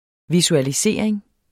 Udtale [ visualiˈseɐ̯ˀeŋ ]